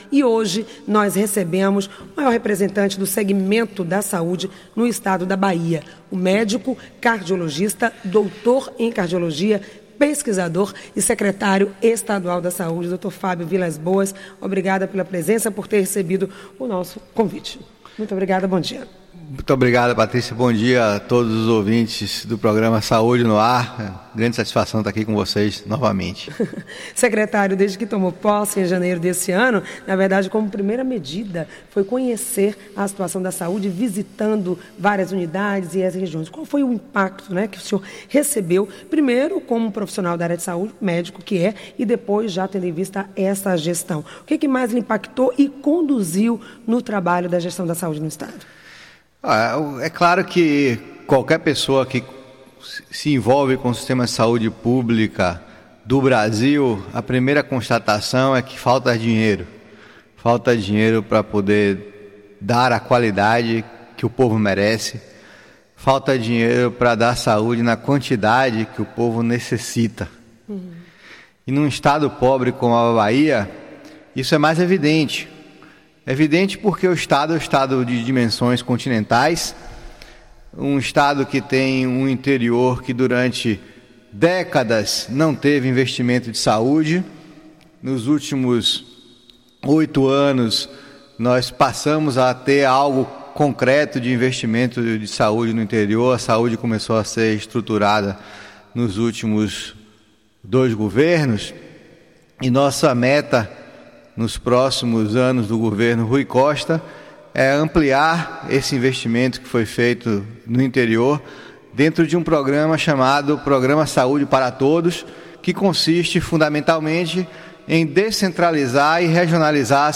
Entrevista com o Secretário da Saúde, Fábio Vilas-Boas